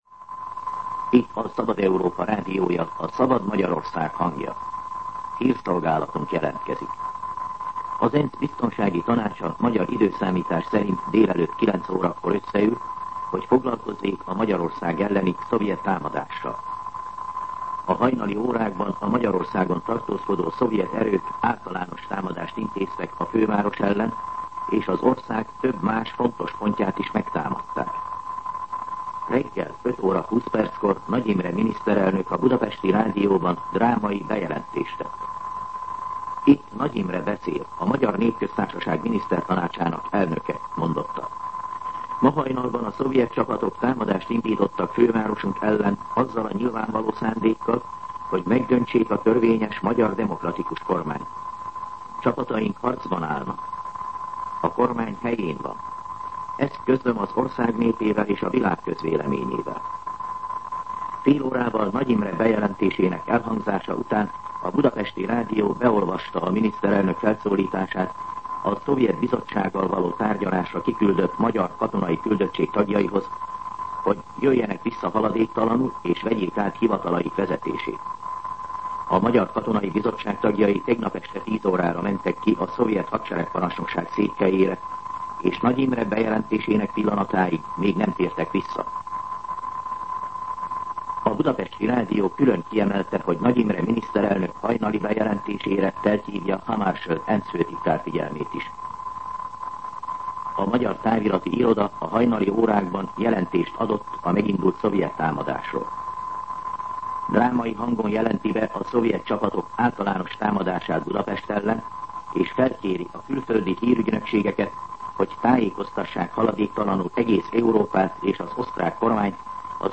Hírszolgálat
MűsorkategóriaHírszolgálat